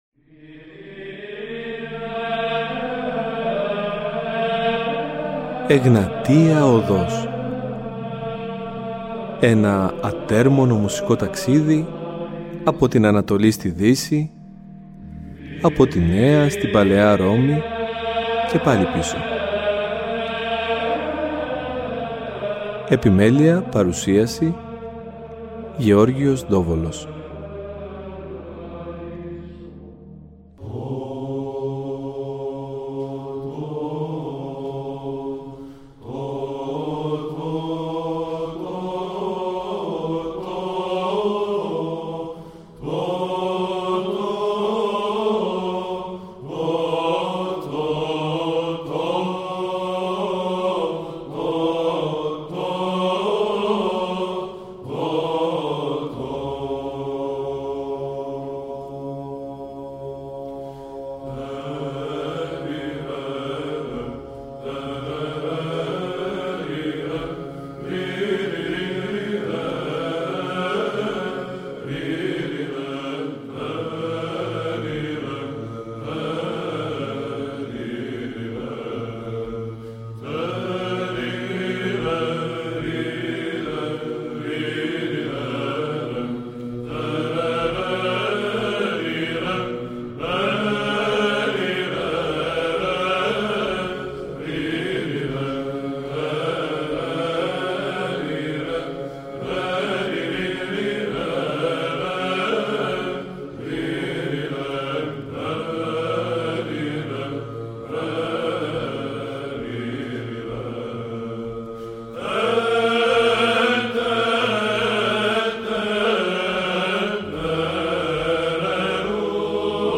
Αυτό το Σαββατοκύριακο θα ασχοληθούμε με τους Ιερούς Κανόνες που κατά καιρούς εξέδωσε η Εκκλησία μέσα στους οποίους αποκρυσταλλώνεται η εικόνα του ιεροψάλτη σύμφωνα με τους Πατέρες και τα κελεύσματα του χριστιανισμού. Θα ακούσουμε επίσης ύμνους από το Δ’ Διεθνές μουσικολογικό συνέδριο που πραγματοποιήθηκε το 2009 με τίτλο «Ψάλατε Συνετώς τω Θεώ», τίτλος που αποτέλεσε και την πηγή έμπνευσης αυτών των εκπομπών.